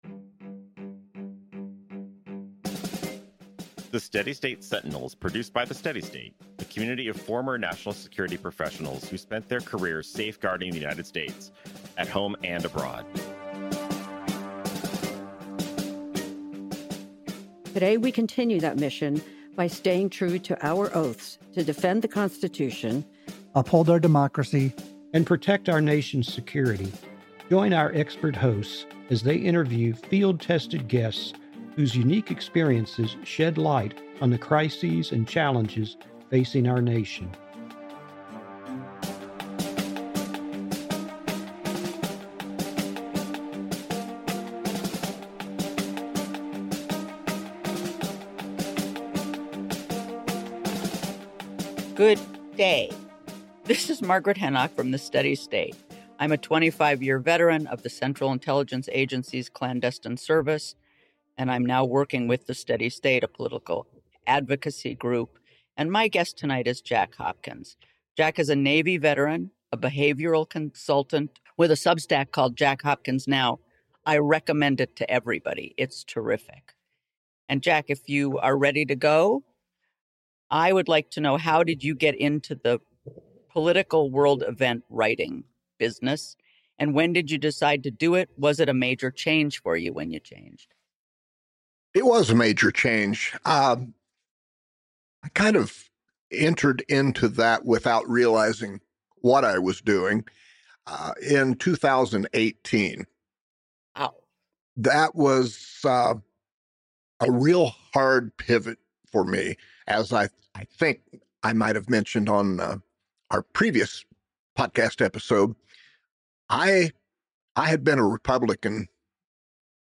Former CIA officer and Navy veteran reveal 3 critical threats to U.S. security—and why emotional paralysis is democracy's biggest enemy.